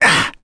Dimael-Vox_Damage_01.wav